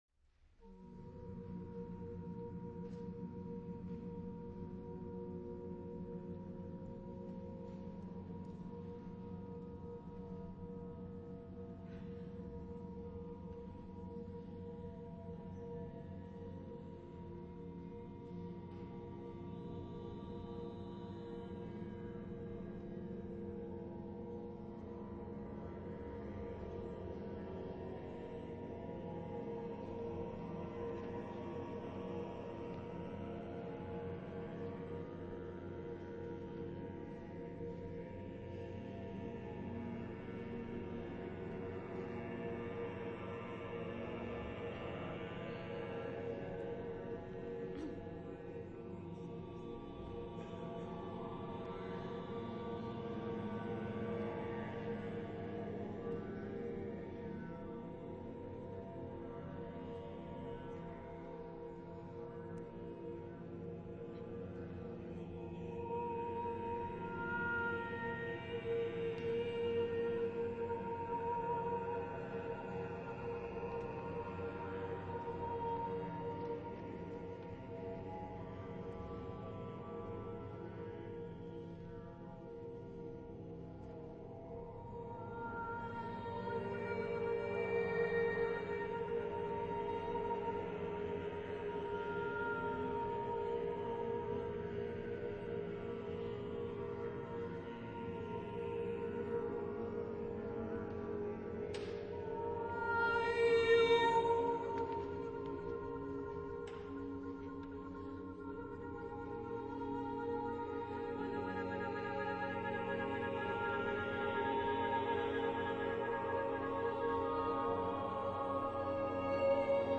Texte en : sans paroles
Genre-Style-Forme : Etude vocale ; Mantra ; Chant phonétique
Type de choeur : 8S-8A-8T-8B  (32 voix mixtes )